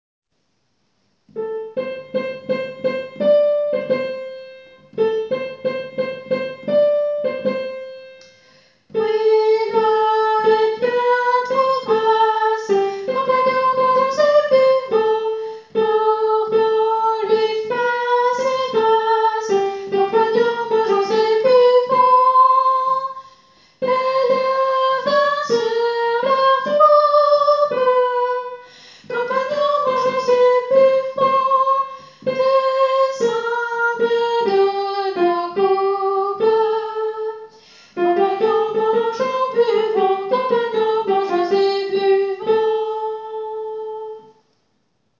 Soprane :
Loie-soprano.wav